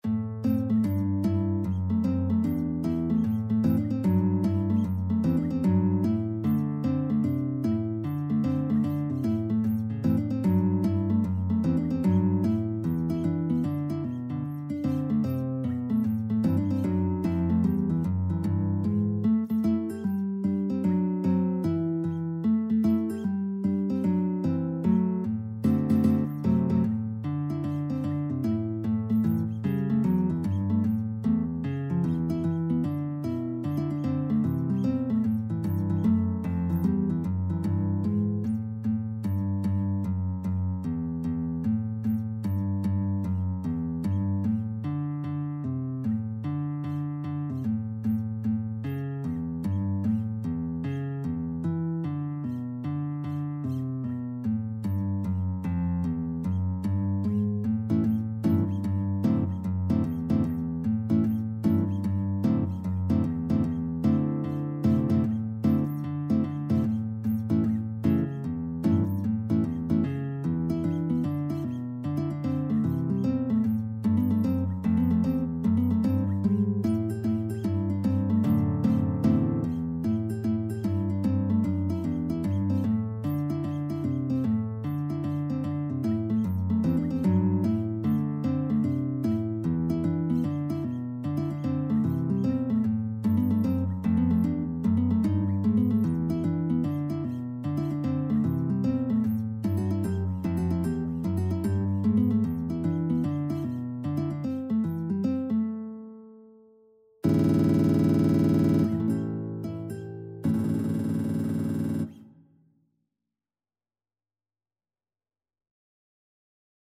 E3-G5
4/4 (View more 4/4 Music)
Guitar  (View more Advanced Guitar Music)
Jazz (View more Jazz Guitar Music)